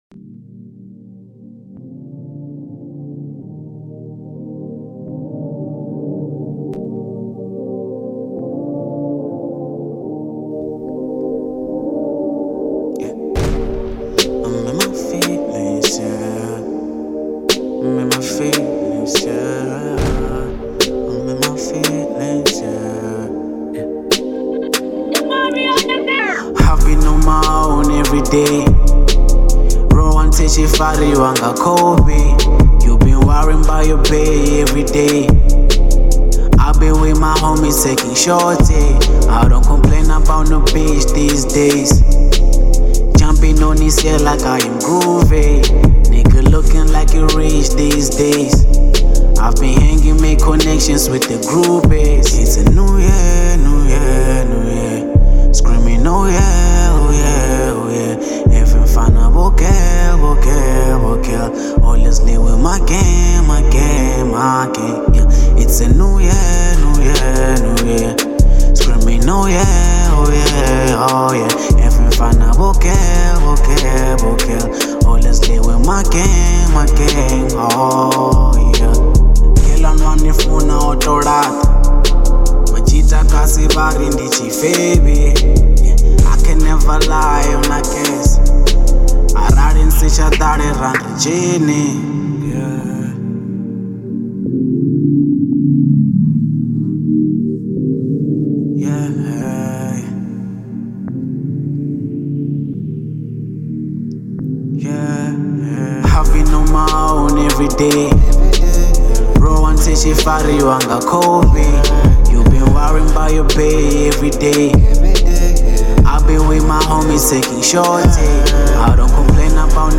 03:06 Genre : Venrap Size